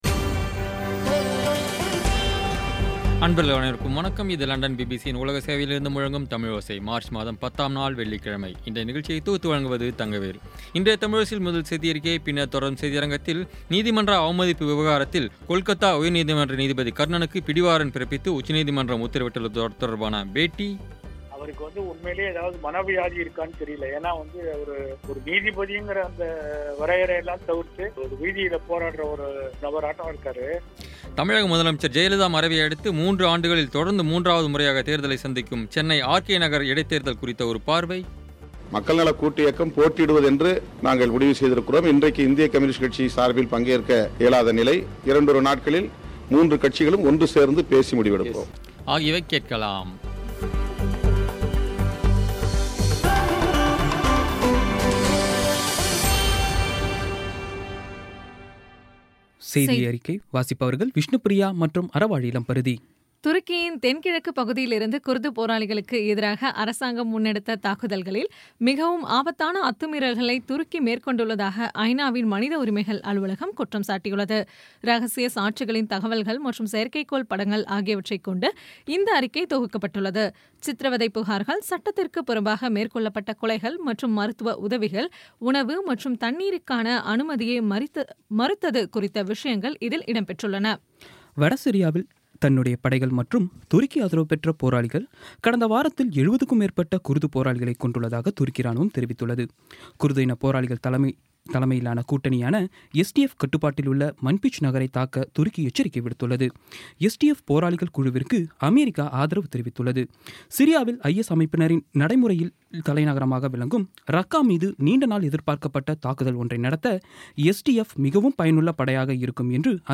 இன்றைய தமிழோசையில், கொல்கத்தா உயர்நீதிமன்ற நீதிபதி கர்ணனுக்கு பிடிவாரண்ட் பிறப்பித்து உச்சநீதிமன்றம் உத்தரவிட்டுள்ளது. இதுகுறித்த செய்தி. இந்த உத்தரவு குறித்து, ஓய்வு பெற்ற சென்னை உயர்நீதிமன்ற நீதிபதி சந்துரு அவர்களின் பேட்டி ஆகியவை கேட்கலாம்.